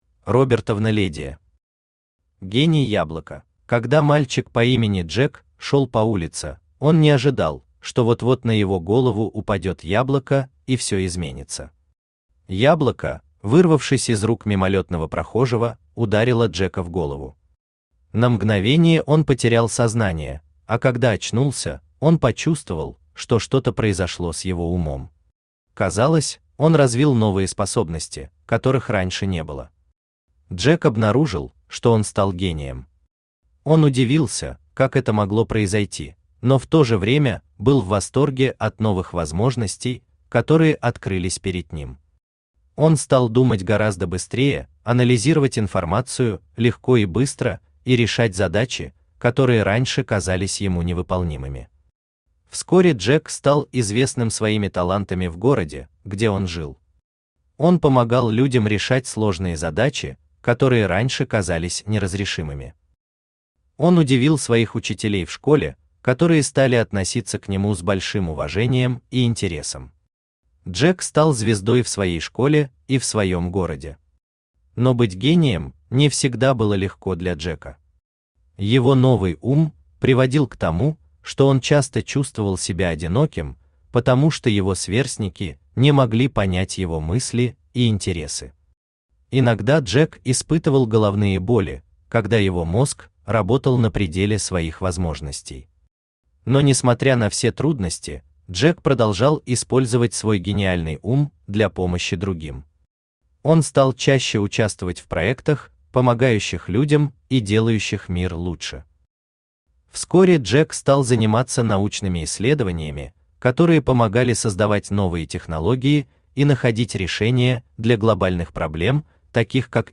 Аудиокнига Гений яблока | Библиотека аудиокниг
Aудиокнига Гений яблока Автор Робертовна Ледия Читает аудиокнигу Авточтец ЛитРес.